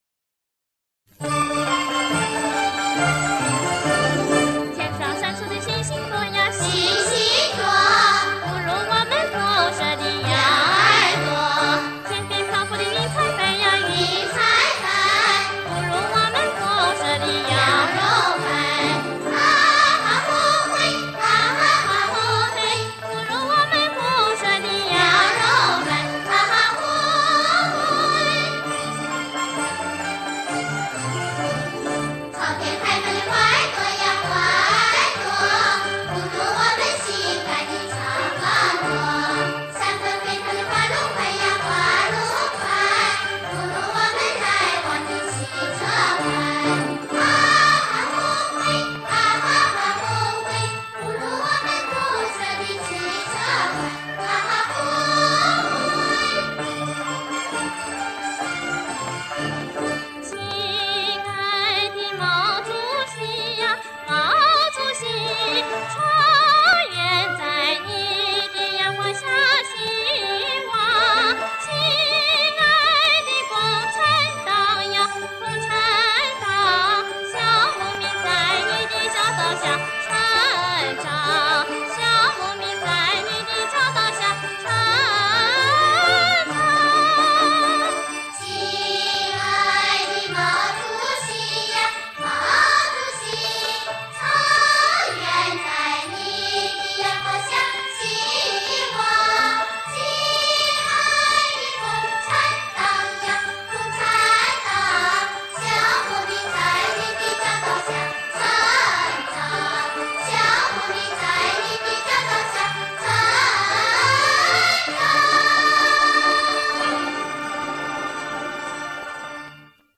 超长极限版本，弥足珍贵，原声电影歌曲大碟，顶尖音频技术处理中国电影博物馆馆藏珍品，史料详实，图文丰富，权威，鲜为人知